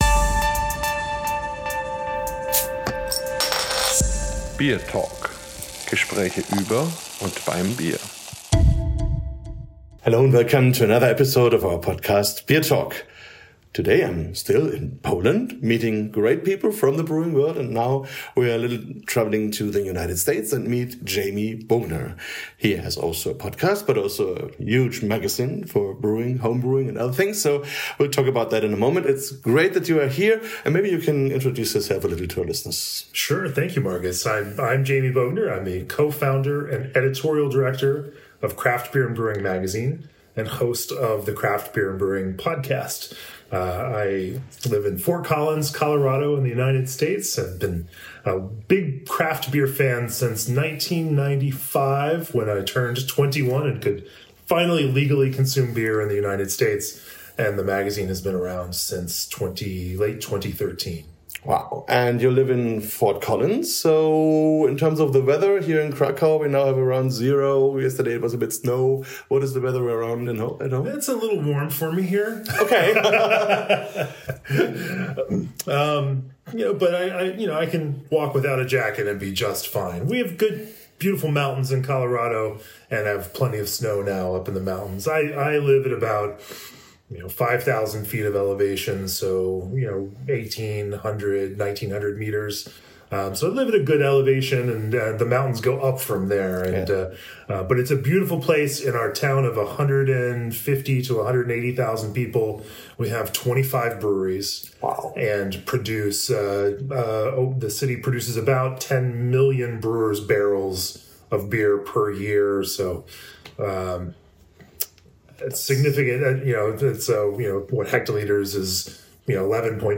Recorded at the Farmhouse Beer Festival in Norway, this conversation is packed with history, humor, and insider insights into the world of Nordic brewing.